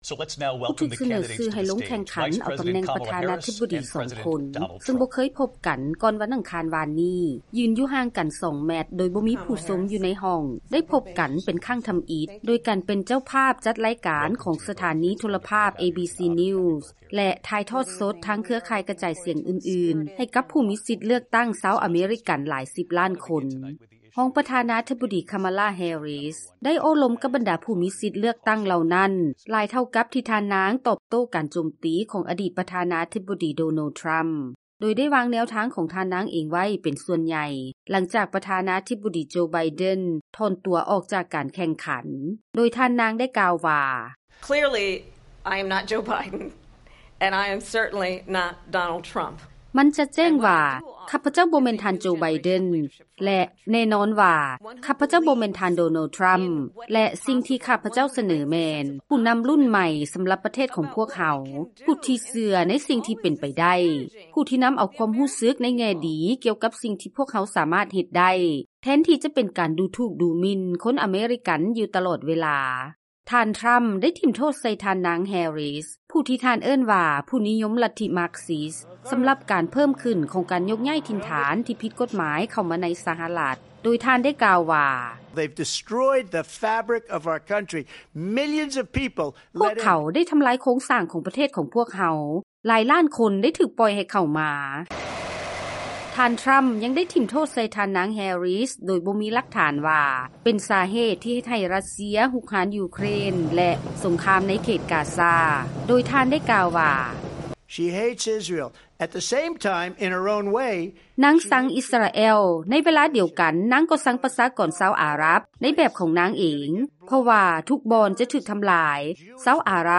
VOA News: